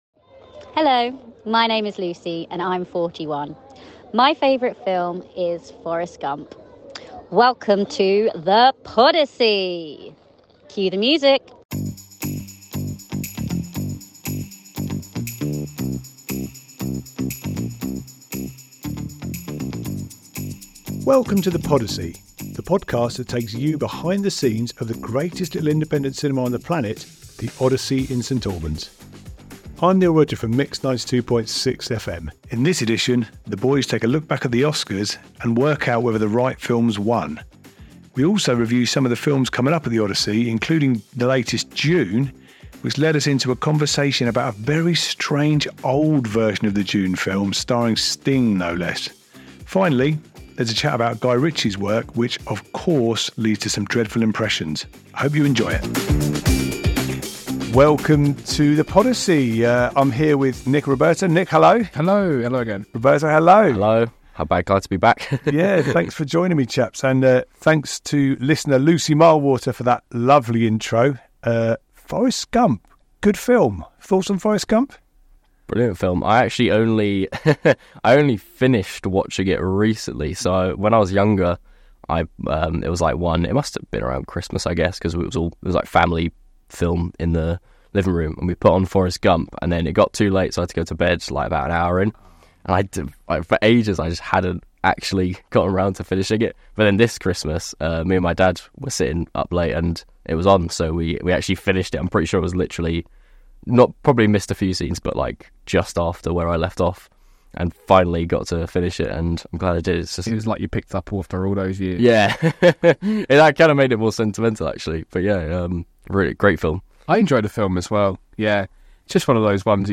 In this episode, the boys discuss the Oscars and the new Dune film (including a bizarre previous attempt at a Dune film starring Sting) and look at what's coming up at the Odyssey this month. We also have a film review from a listener and discuss the films of Guy Ritchie, which leads to some dreadful impressions.
This podcast is for you if you like going to the cinema, in-depth film discussions, bad impressions of film stars and a confused host asking questions.